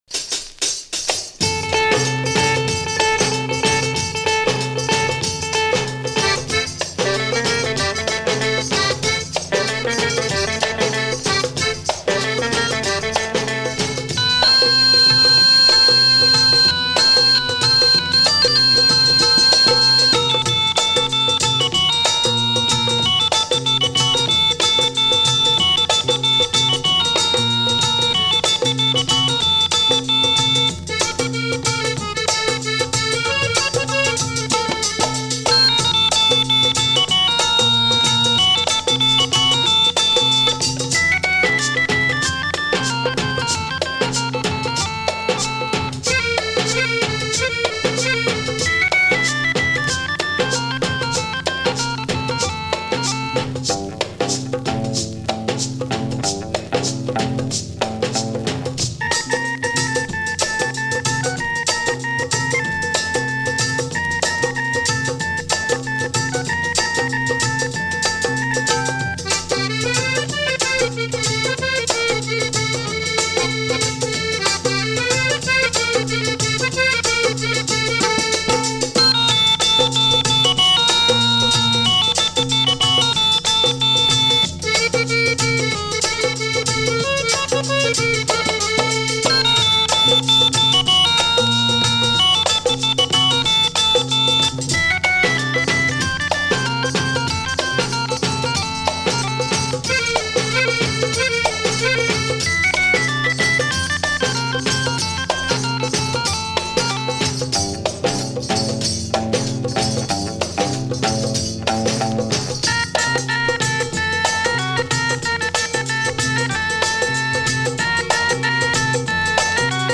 Lollywood Steel Guitar?